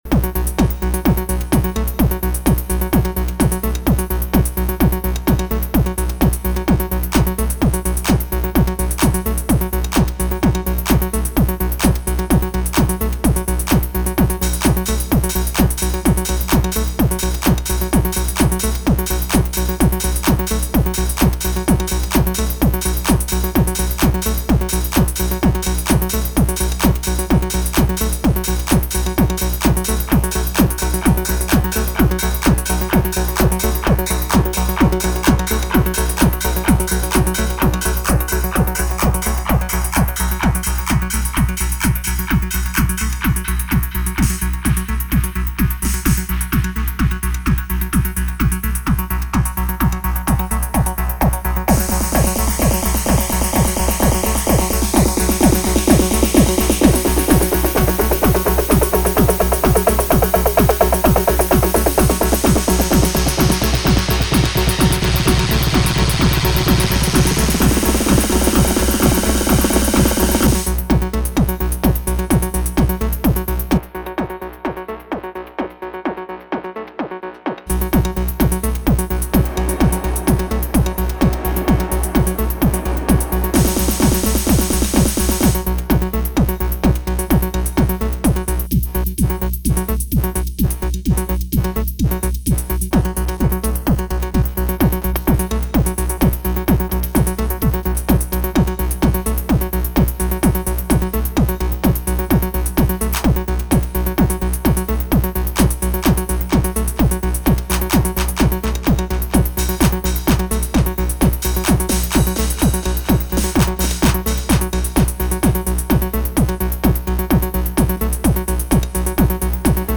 It´s a very simple 16 bar loop (the first seconds are without effects), then it with Ignites samples/effects and nothing else.